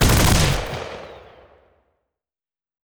wav / general / combat / weapons / mgun / fire2.wav
fire2.wav